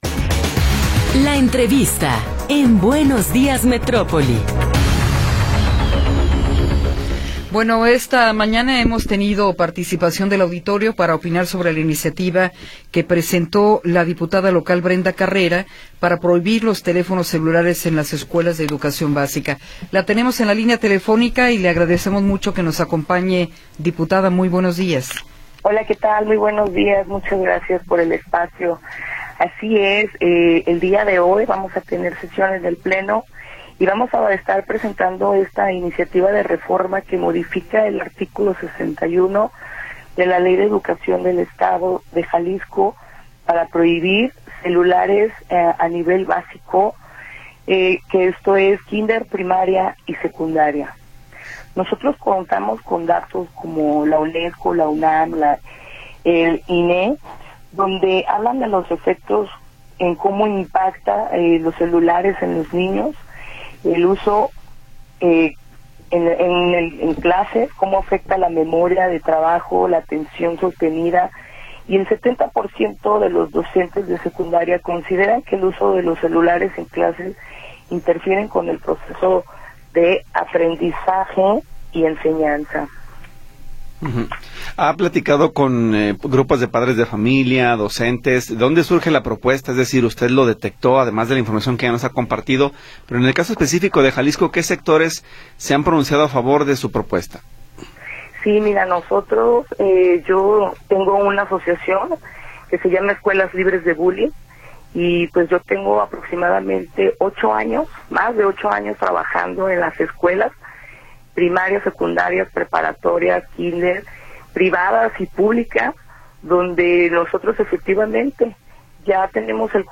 Entrevista con Brenda Carrera García
Brenda Carrera García, diputada local, nos habla sobre su iniciativa para prohibir los teléfonos celulares en las escuelas de educación básica.